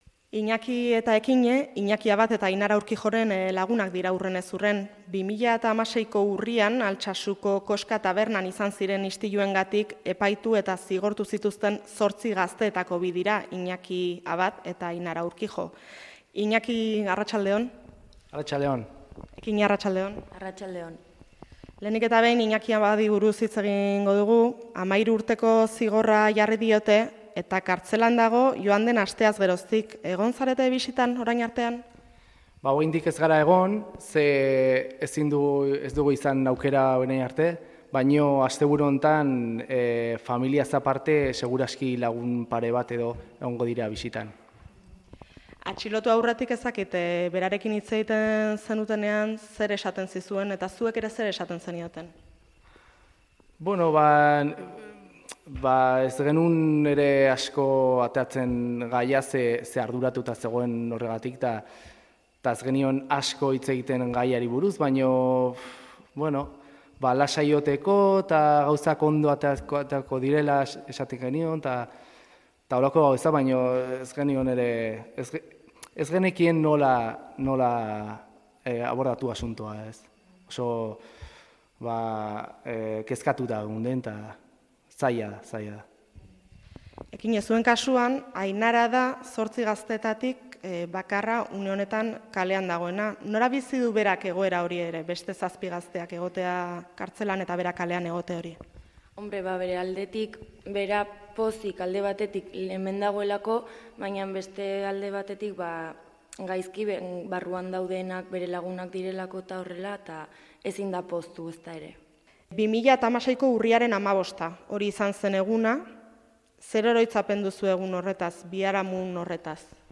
Euskadi Irratiko Amarauna Altsasun izan da